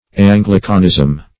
Anglicanism \An"gli*can*ism\, n.
anglicanism.mp3